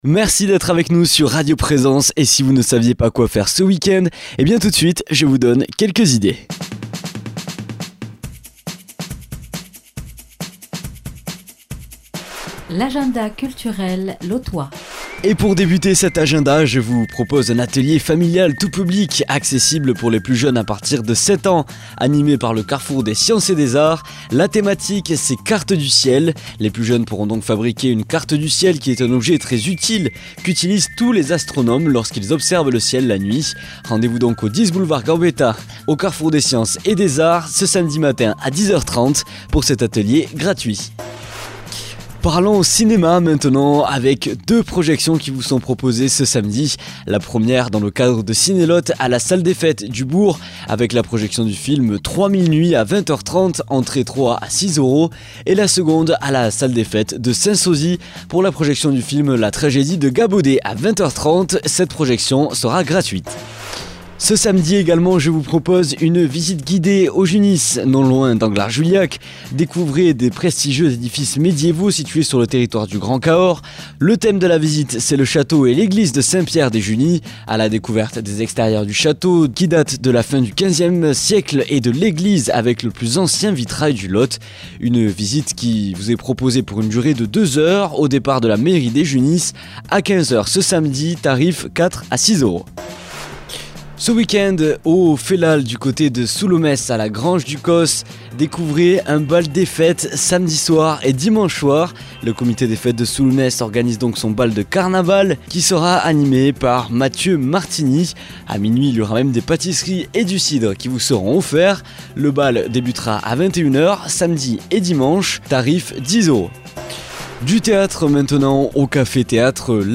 Blues
Présentateur